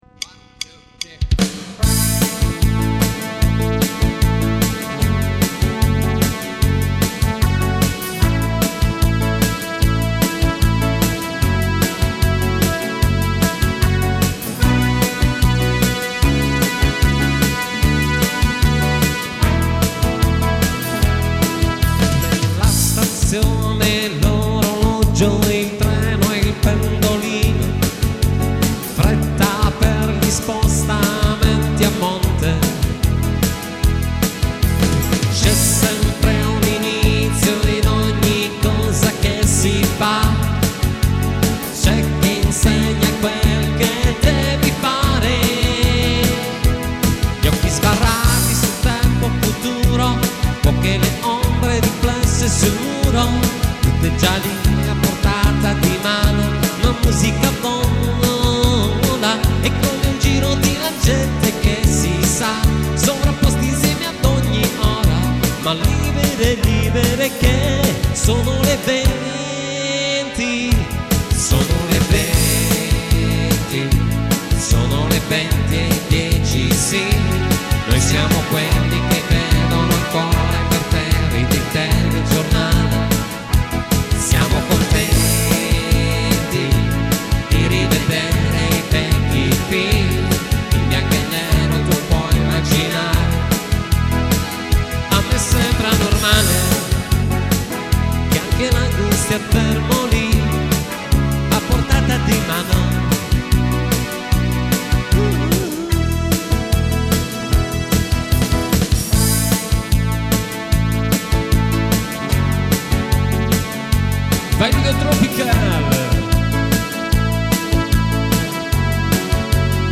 chitarra
tastiera e fisarmonica